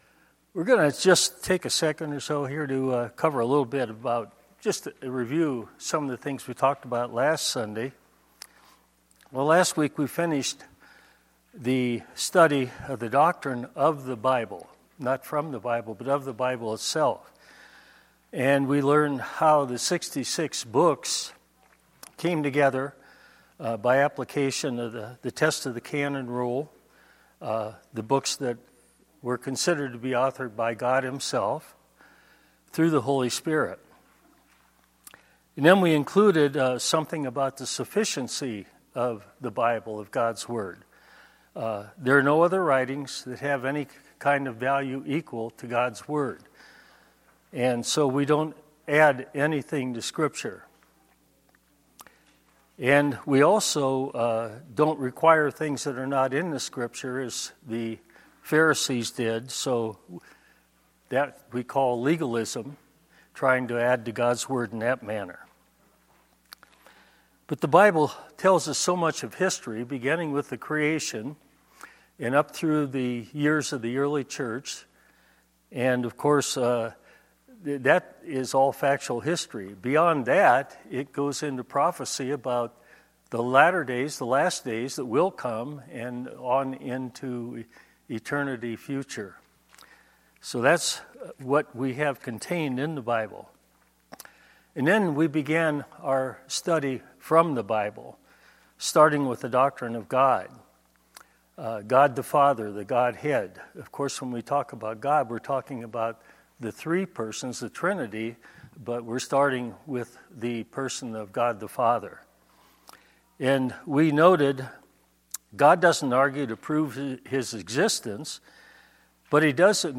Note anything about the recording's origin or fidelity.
Sorry, there was a power failure at about 22 minutes into the lesson. There will be a bit of a skip until power was restored.